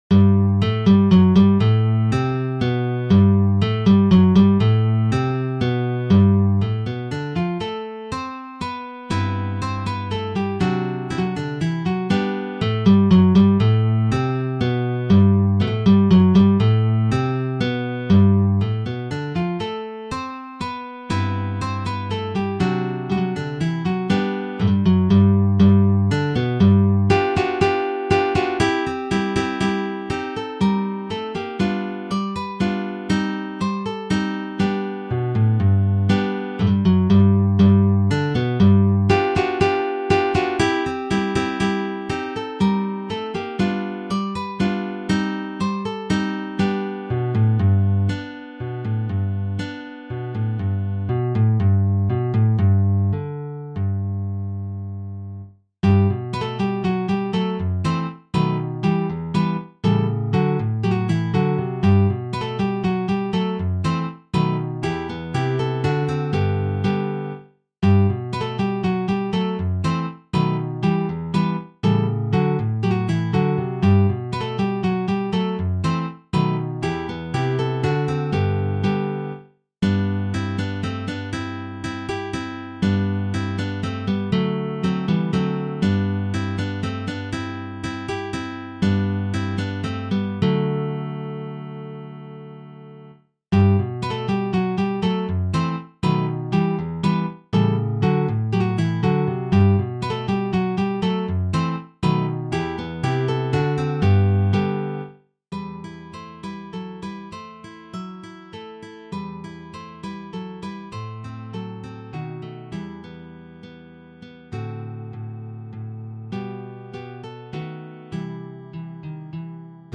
Rhythmically, it is rather simple.